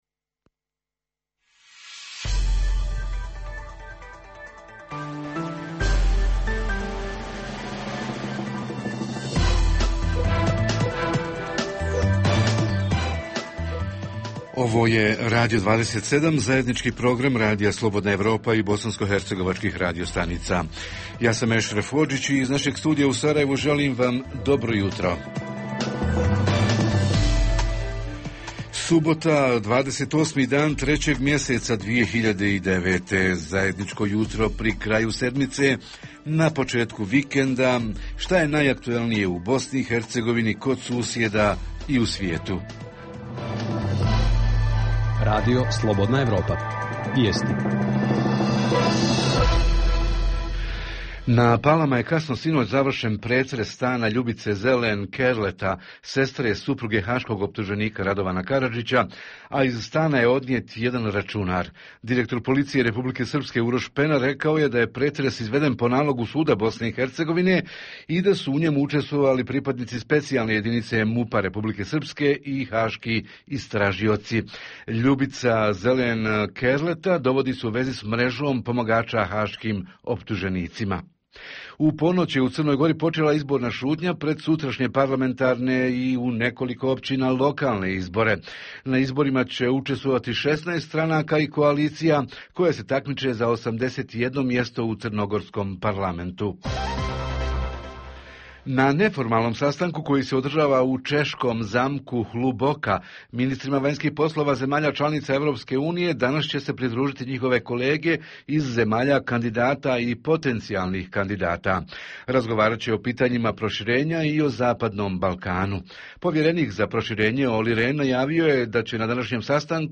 Jutarnji program za BiH koji se emituje uživo.
Redovna rubrika Radija 27 subotom je “Estrada i show bussines”. Redovni sadržaji jutarnjeg programa za BiH su i vijesti i muzika.